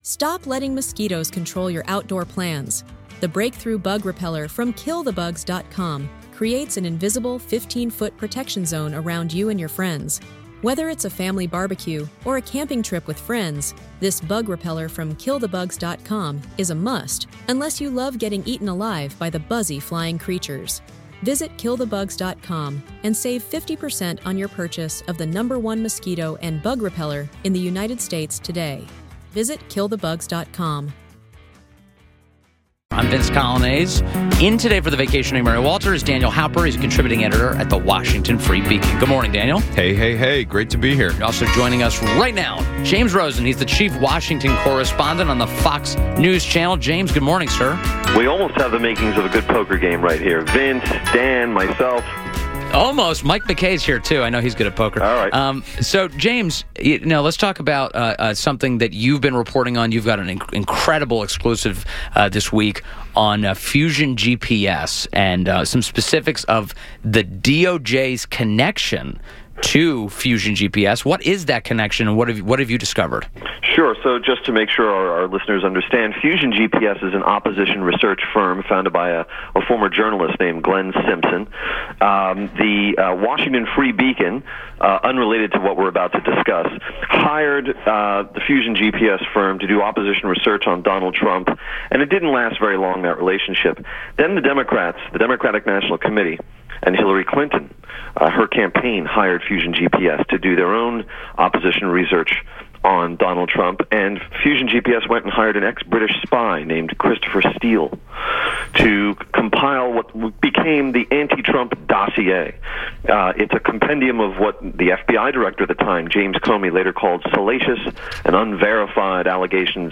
INTERVIEW - JAMES ROSEN - Chief Washington Correspondent, Fox News